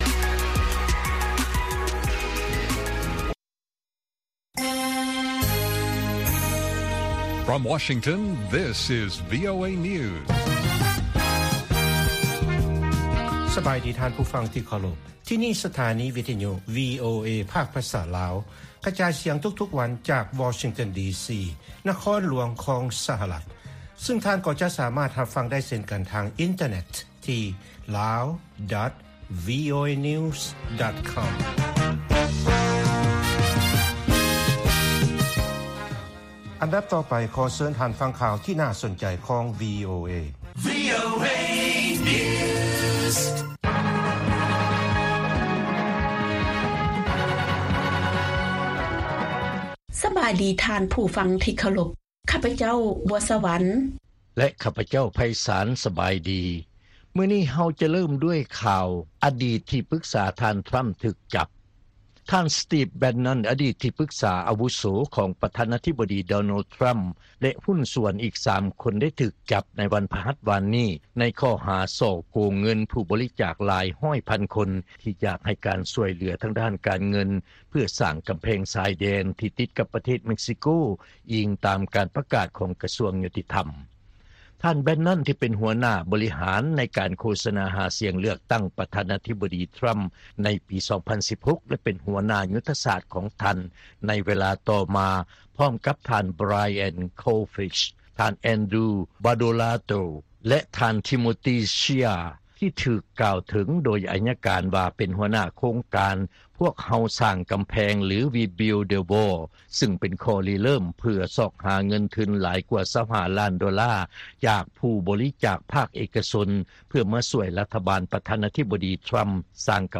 ລາຍການກະຈາຍສຽງຂອງວີໂອເອ ລາວ
ວີໂອເອພາກພາສາລາວ ກະຈາຍສຽງທຸກໆວັນ. ຫົວຂໍ້ຂ່າວສໍາຄັນໃນມື້ນີ້ມີ: 1) ສະຫະລັດ ກະຕຸ້ນໃຫ້ ວາງມາດຕະການລົງໂທດ ຕໍ່ອີຣ່ານ ຕໍ່ໄປອີກ ຢູ່ທີ່ອົງການສະຫະປະຊາຊາດ.